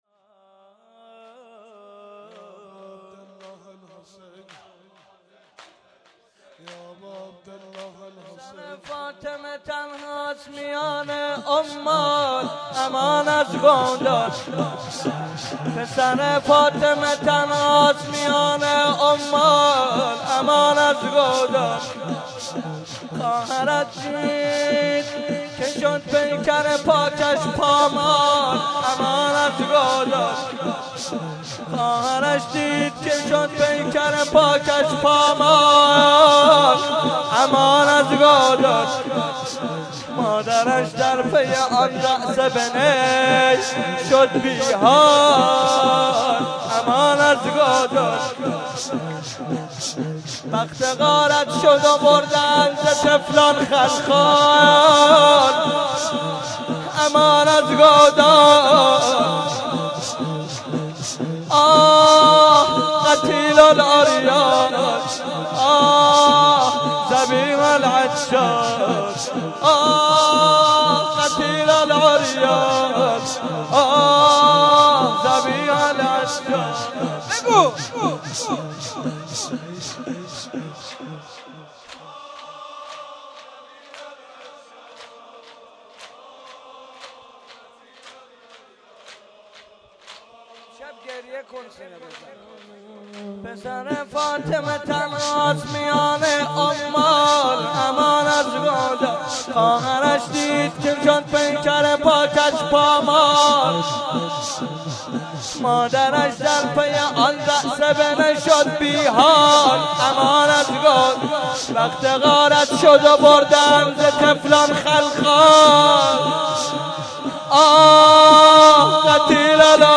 04.sineh zani.mp3